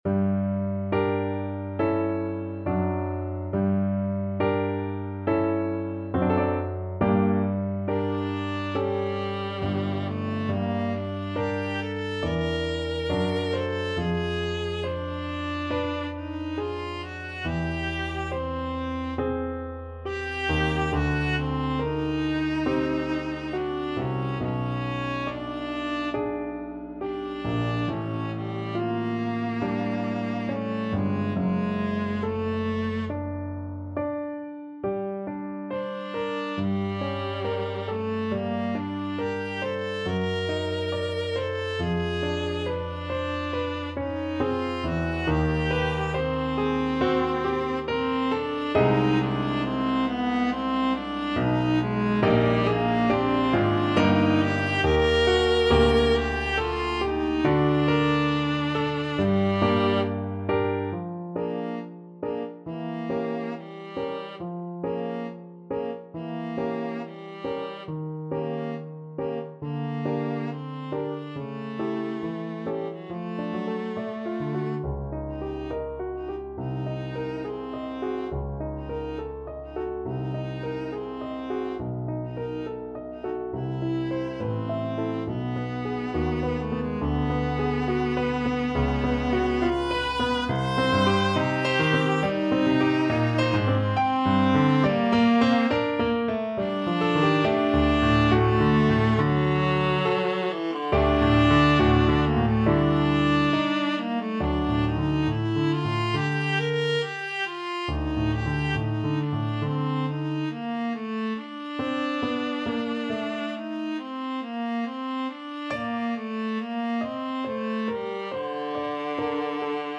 Viola version
4/4 (View more 4/4 Music)
~ = 69 Andante con duolo
Classical (View more Classical Viola Music)